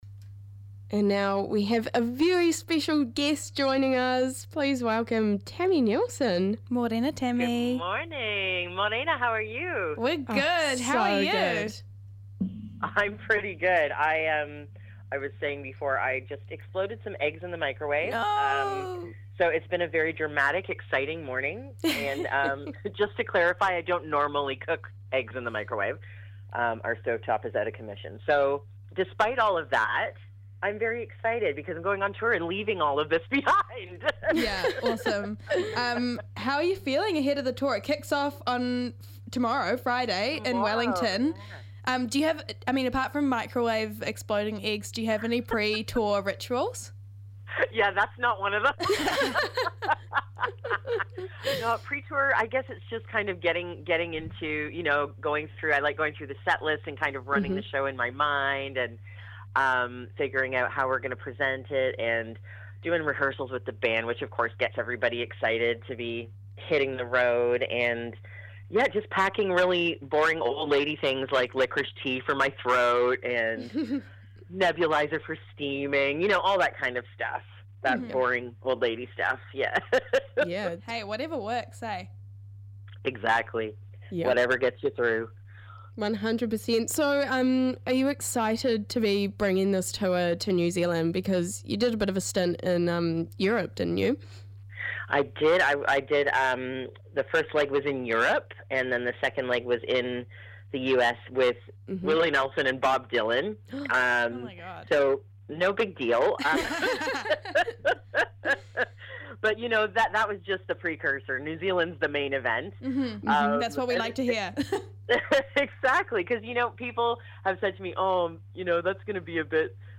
Guest Interview w/ Tami Neilson: Rāpare October 2, 2025